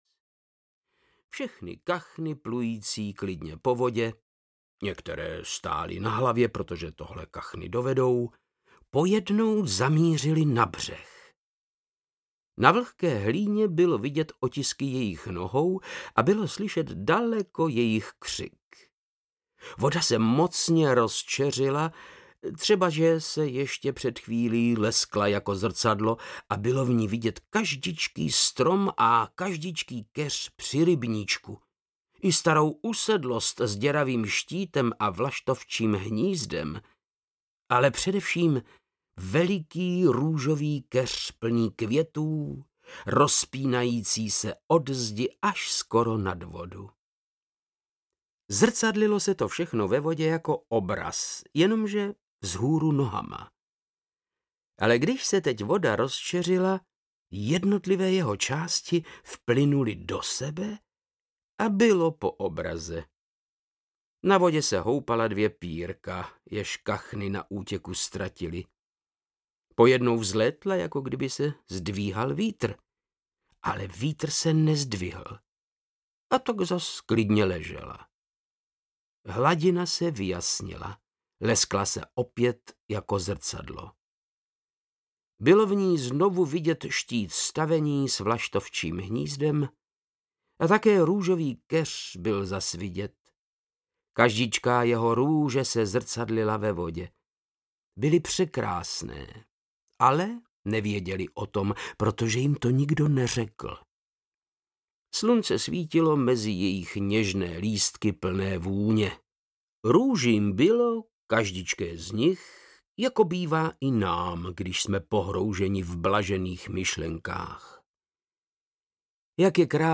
Sousedé audiokniha
Ukázka z knihy